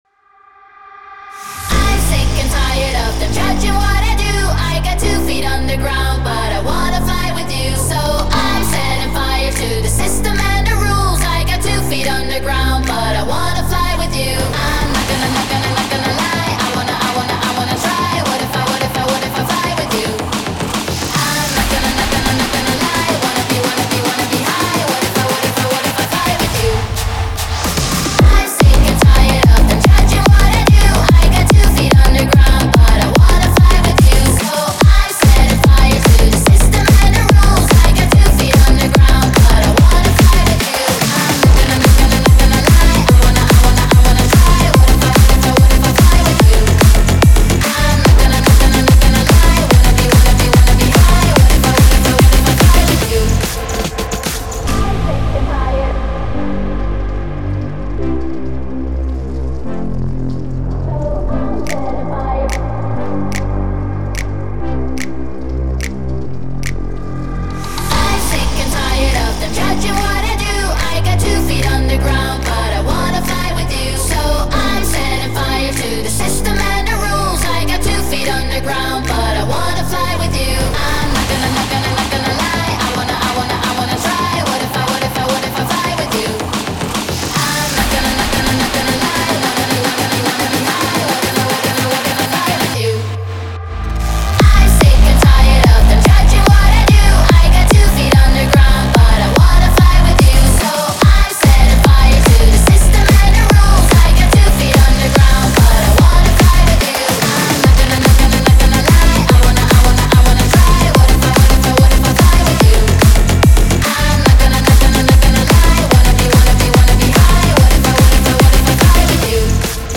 это трек в жанре электронной музыки с элементами попа